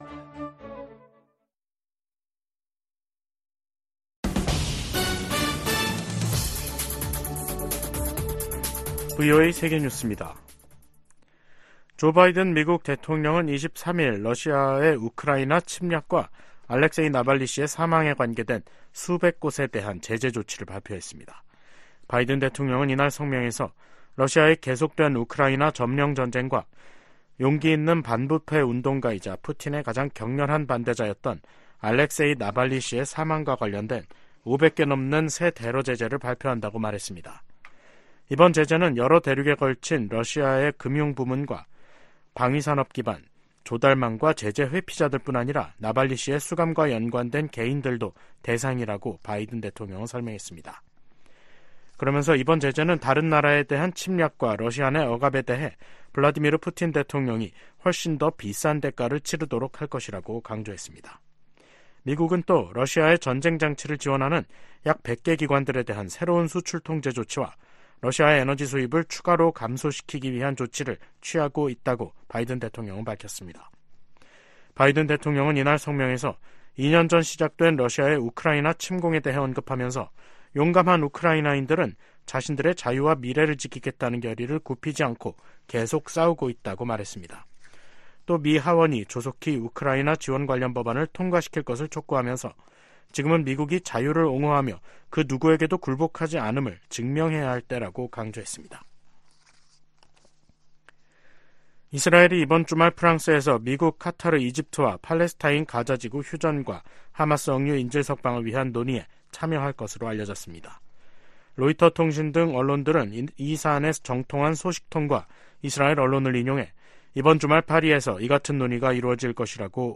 VOA 한국어 간판 뉴스 프로그램 '뉴스 투데이', 2024년 2월 23일 3부 방송입니다. 미국·한국·일본 외교 수장들이 리우데자네이루 주요20개국(G20) 외교장관회의 현장에서 역내 도전 대응 방안을 논의했습니다. 보니 젠킨스 미 국무부 군비통제·국제안보 차관이 북한-러시아의 군사 협력을 심각한 우려이자 심각한 위협으로 규정했습니다.